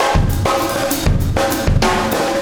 Extra Terrestrial Beat 14.wav